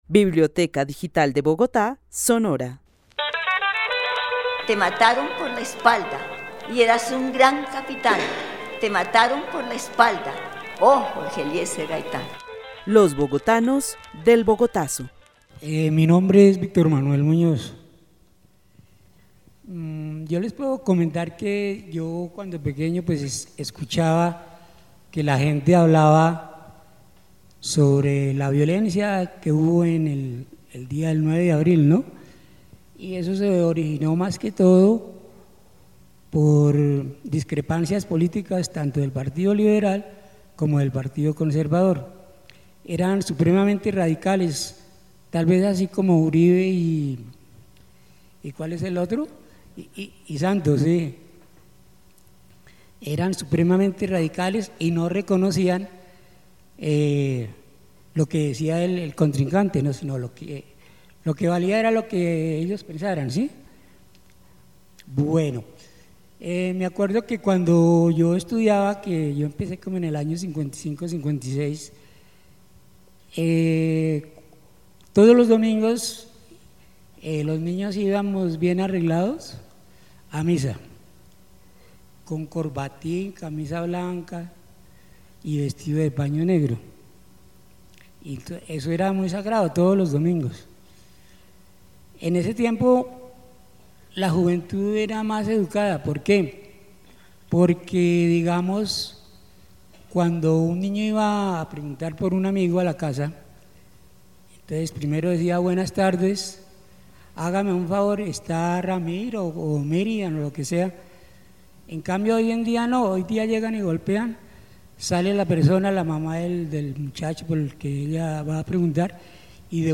Narración oral de los hechos sucedidos en Bogotá el 9 de abril de 1948 y sobre los cambios de hábitos y prácticas entre los años 50 y la época actual. El narrador comenta las costumbres en su niñez.. El testimonio fue grabado en el marco de la actividad "Los bogotanos del Bogotazo" con el club de adultos mayores de la Biblioteca Carlos E. Restrepo.